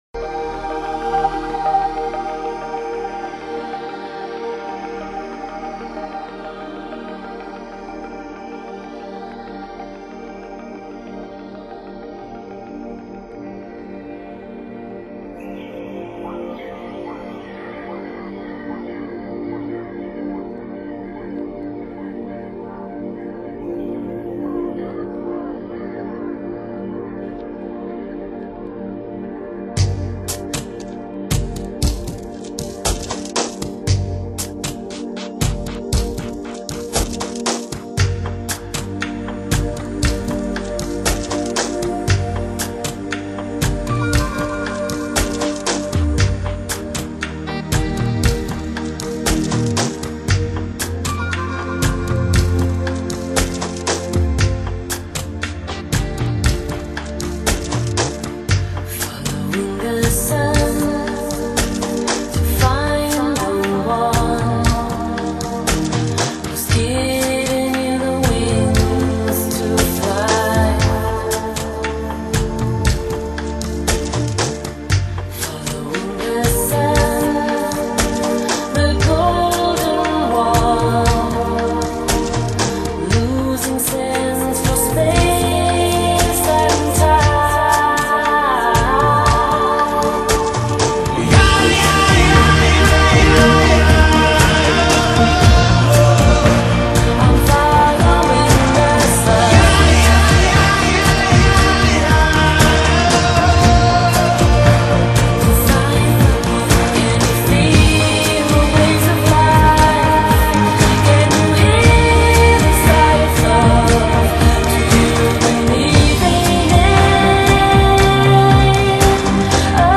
发烧音乐 新音乐 迷幻
强劲的节奏，动听的旋律和女声迷幻般的吟唱，使这首歌极具感染力